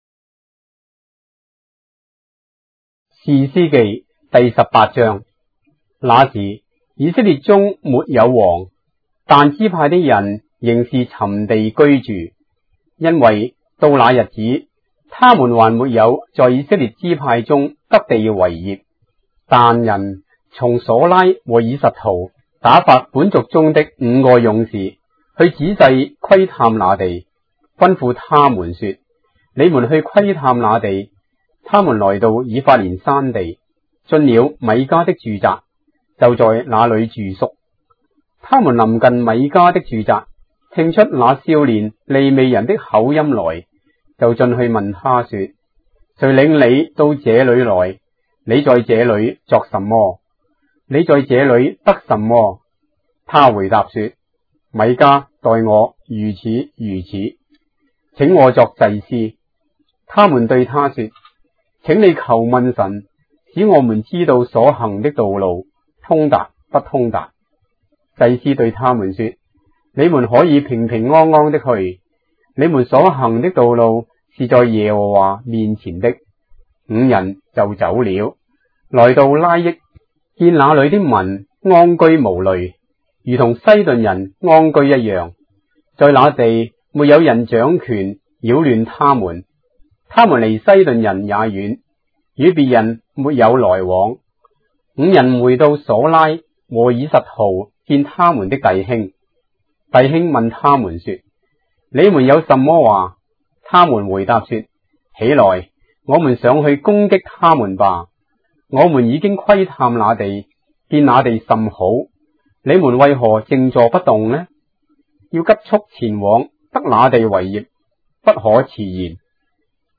章的聖經在中國的語言，音頻旁白- Judges, chapter 18 of the Holy Bible in Traditional Chinese